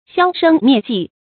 消聲滅跡 注音： ㄒㄧㄠ ㄕㄥ ㄇㄧㄝ ˋ ㄐㄧˋ 讀音讀法： 意思解釋： 不公開講話，不出頭露面。形容隱藏起來，不再出現。